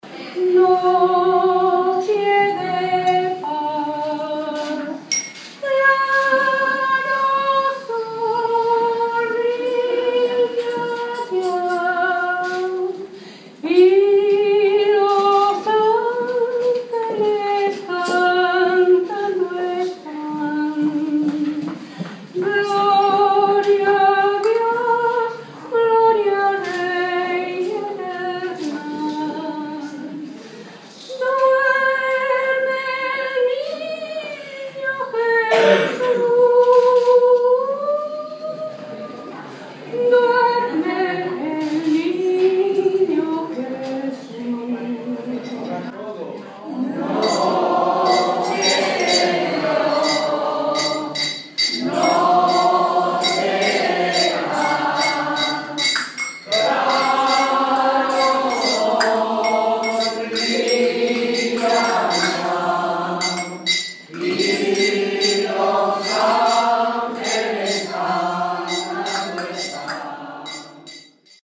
Familia y Bienestar Social: El Coro del Centro de Mayores Juan XXIII deleita a los mostoleños en plena calle
Audio de un villancico interpretado por el Coro de Mayores del Centro Juan XXII de Móstoles.